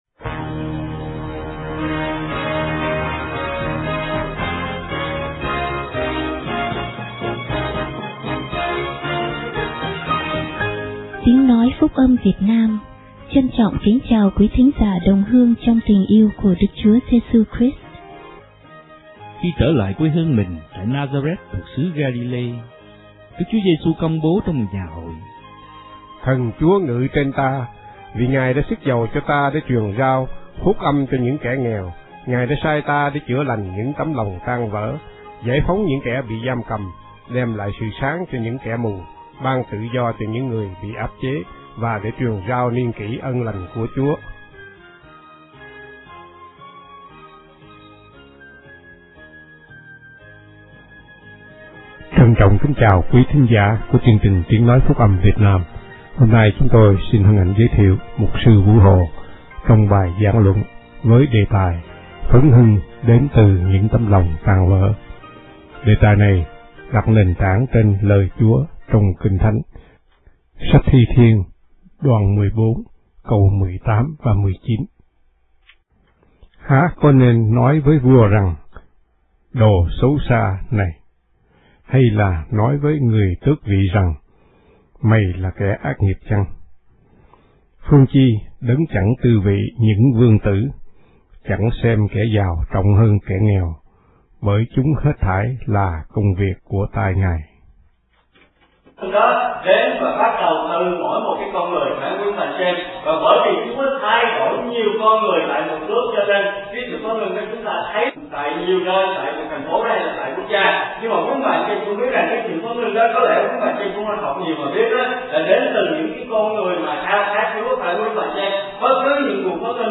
Loạt Bài: Hội Thánh Phúc Âm Việt Nam Westminster